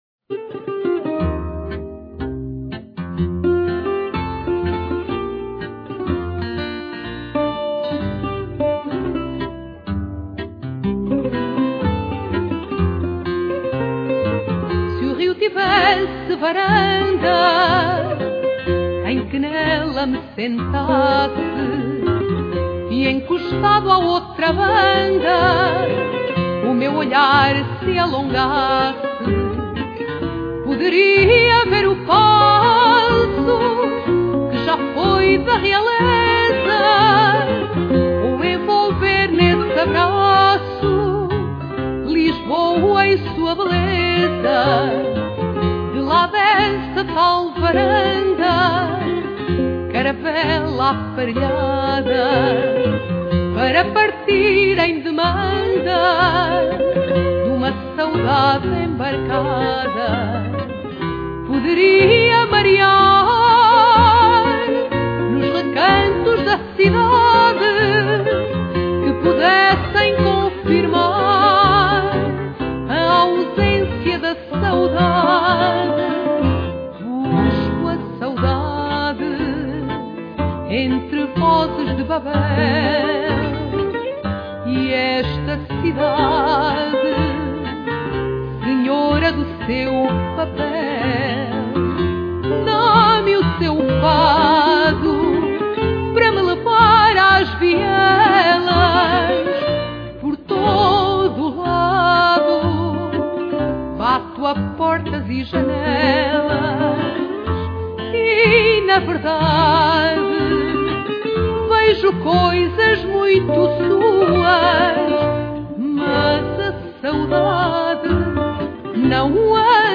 Voz
Guitarra
Viola Baixo